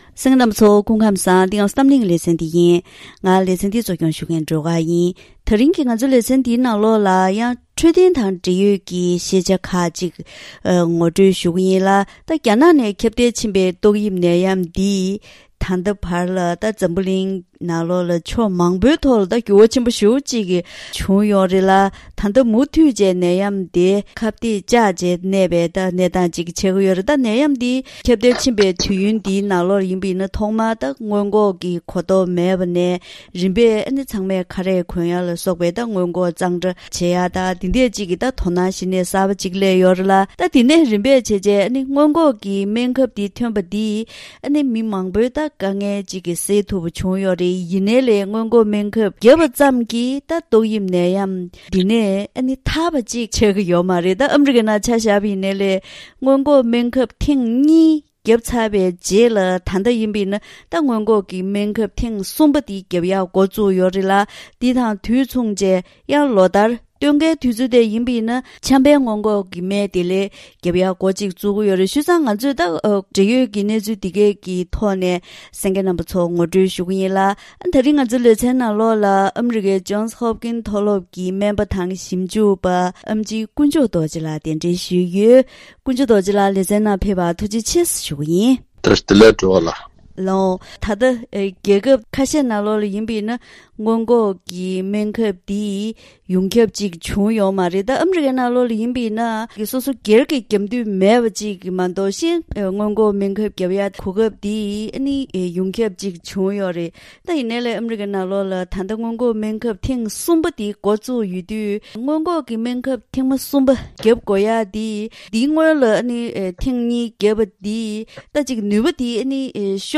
ད་རིང་གི་གཏམ་གླེང་ཞལ་པར་ལེ་ཚན་ནང་ཏོག་དབྱིབས་ནད་ཡམས་ཀྱི་སྔོན་འགོག་སྨན་ཁབ་ཐོན་ན་ཡང་ཉེན་ཁ་མུ་མཐུད་གནས་པའི་སྐབས་དེར་སྟོན་དུས་སུ་ཆམ་རིམས་ཀྱི་སྔོན་འགོག་སྨན་ཁབ་ཀྱང་རྒྱབ་འགོ་བཙུགས་ཡོད་ལ། ཏོག་དབྱིབས་ནད་ཡམས་ཀྱི་སྔོན་འགོག་སྣོན་ཁབ་ཀྱང་རྒྱབ་འགོ་བཙུགས་ཡོད་པས། མུ་མཐུད་གནས་བཞིན་པའི་ཏོག་དབྱིབས་ནད་ཡམས་ལ་སྣང་ཆུང་གཏོང་མི་རུང་བ་སོགས་འབྲེལ་ཡོད་སྐོར་སྨན་པ་དང་ལྷན་དུ་བཀའ་མོལ་ཞུས་པ་ཞིག་གསན་རོགས་གནང་།